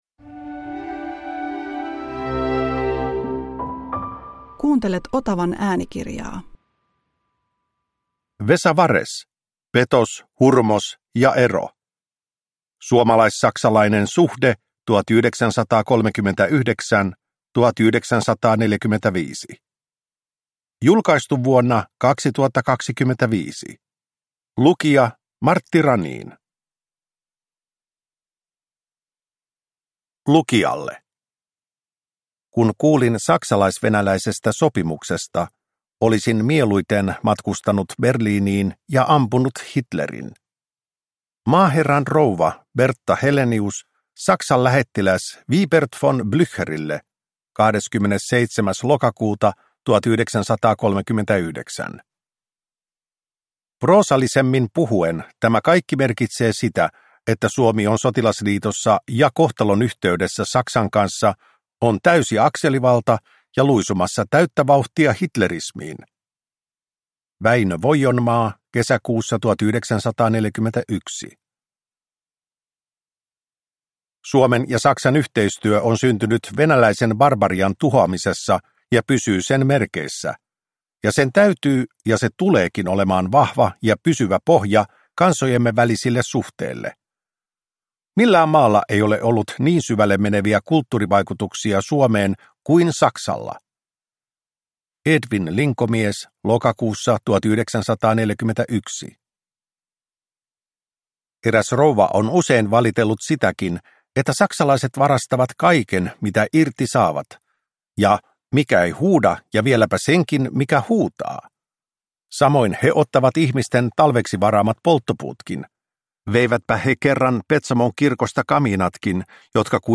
Petos, hurmos, ero – Ljudbok